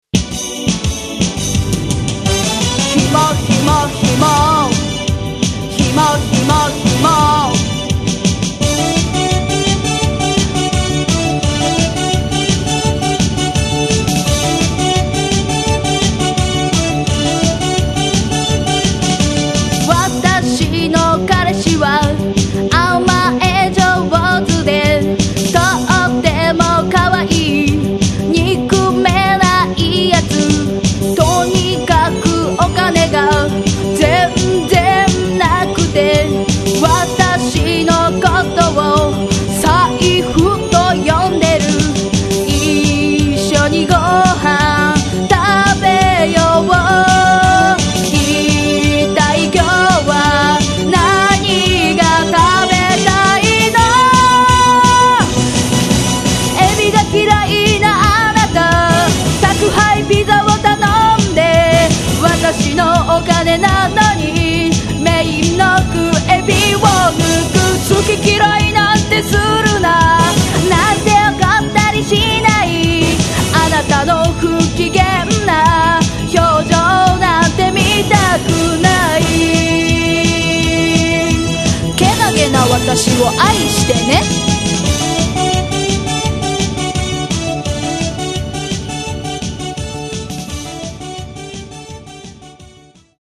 ヒモ男への愛を歌ったダンサブルなナンバー。